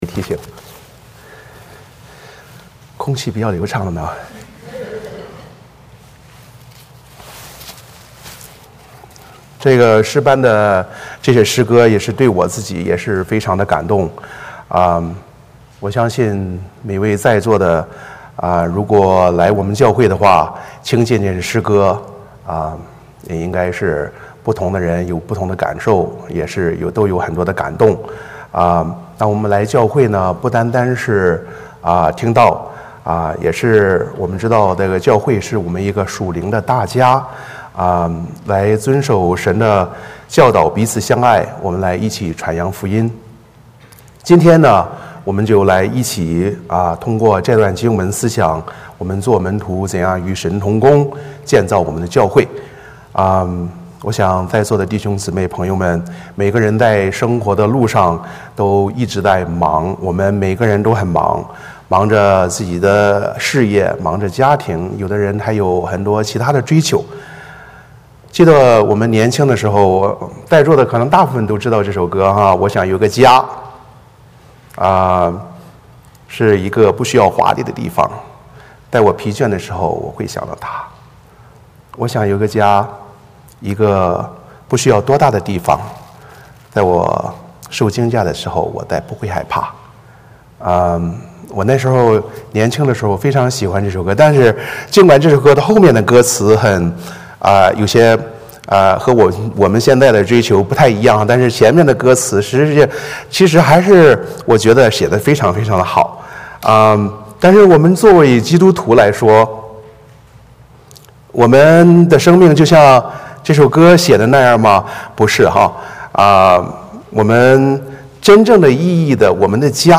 宣召 诗篇 43:3-4 诗歌 / 祷告 耶稣的名 带我进入祢的同在 圣灵请祢来 奉献 活出爱 读经 使徒行传 2:41-47 证道 门徒生活之 〝与神同工，建造教会〞 回应诗 我愿为祢去 三一颂 普天之下万国万民，齐声赞美父子圣灵；三位一体同荣同尊，万有之源万福之本。
Passage: 使徒行传 2:41-47 Service Type: 主日崇拜 欢迎大家加入我们的敬拜。